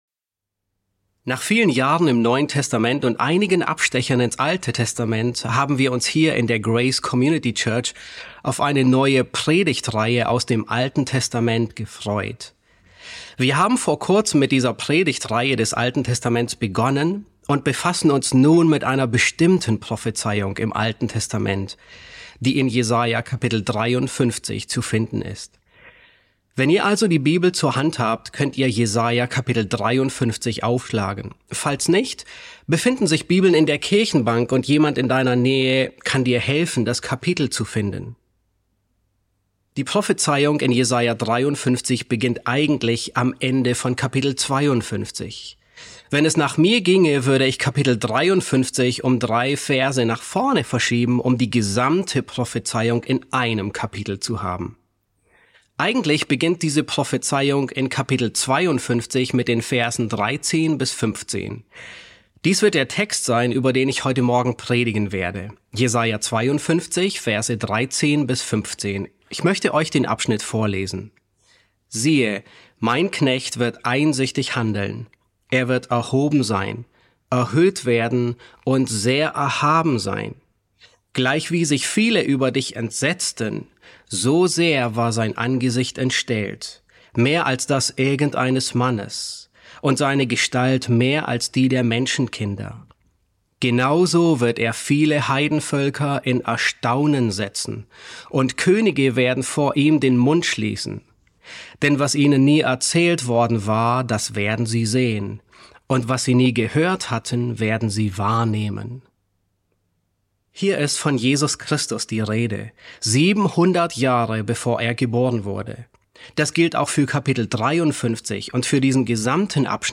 Predigten auf Deutsch Podcast